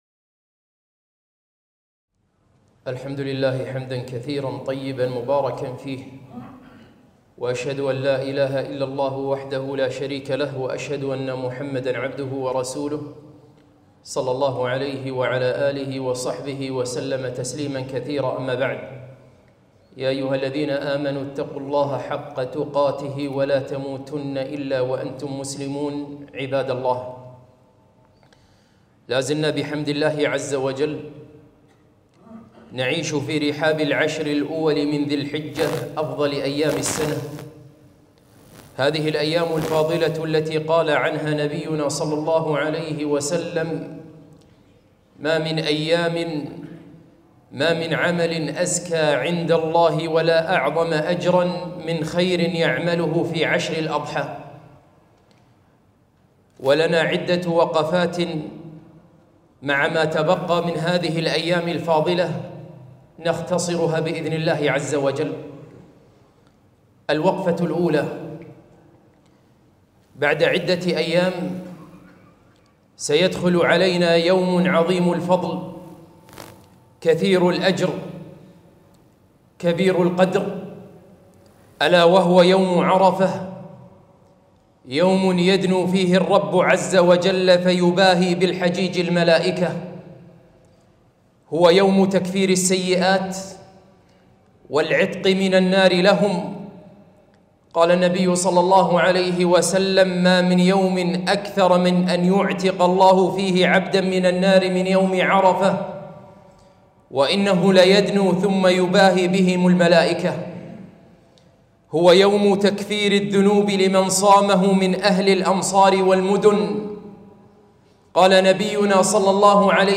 خطبة - خطبة الجمعة يوم عرفة وعيد الأضحى